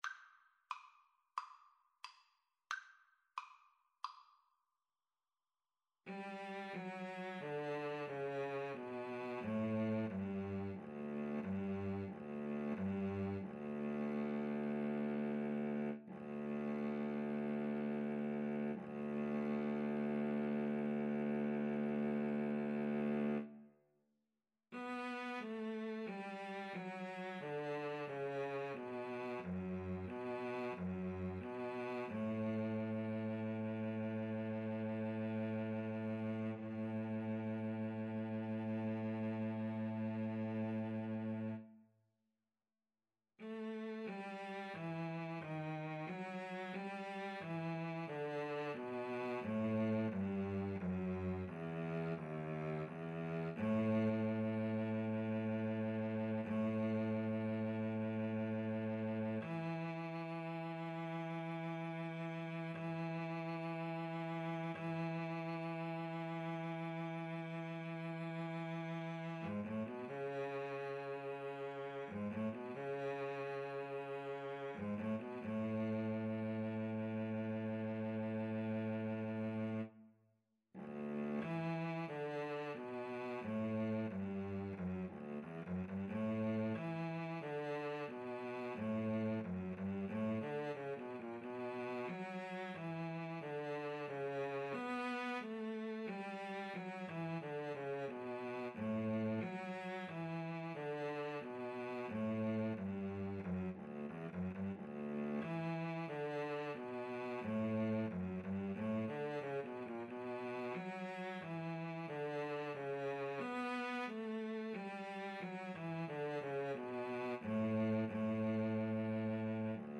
Ruhig bewegt = c. 90
Classical (View more Classical Cello Duet Music)